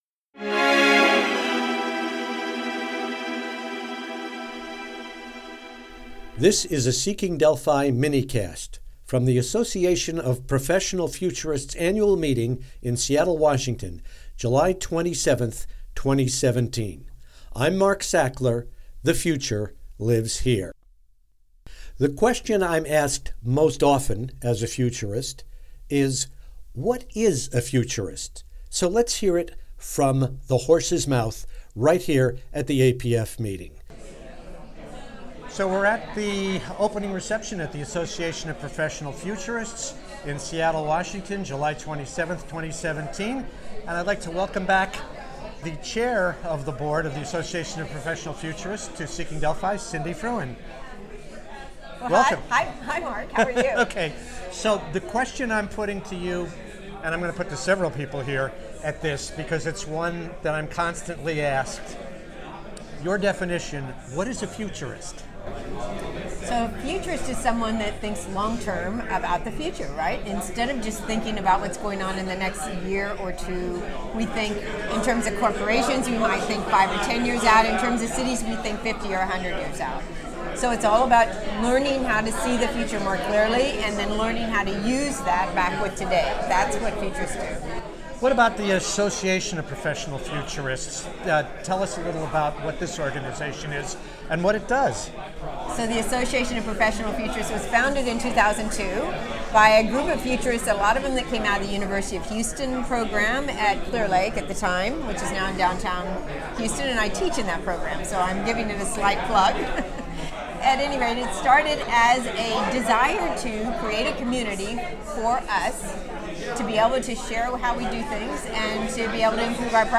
From the Association of Professional Futurists annual meeting, Seattle, Washington, July 27, 2017.